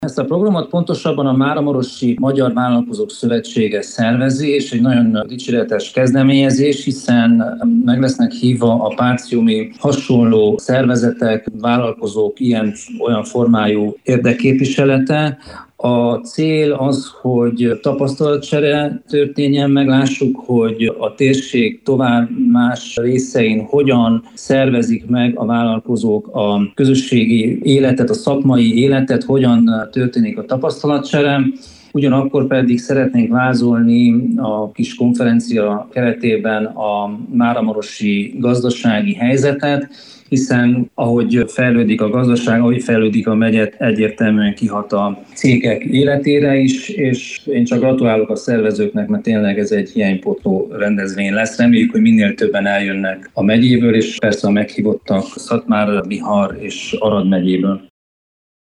A rendezvényről Pintér Zsolt Máramaros megyei tanácsos beszélt rádiónknak.